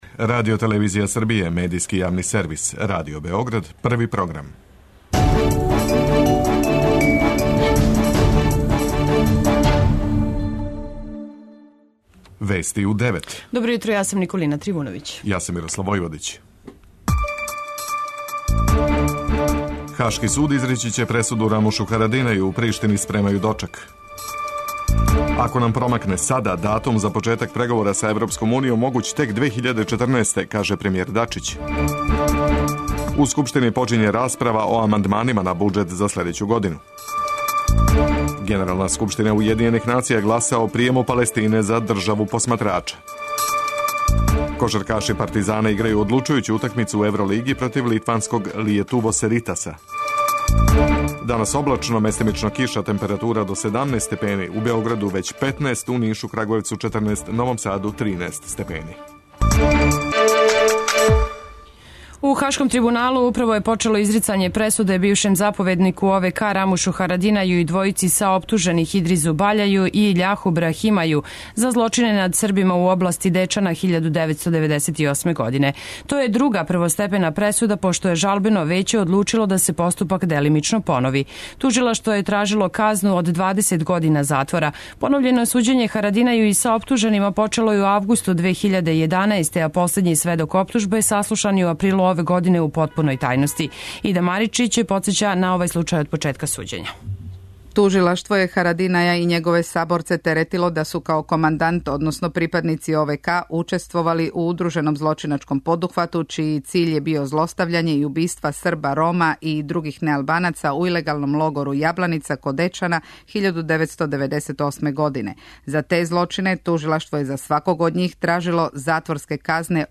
Вести у 9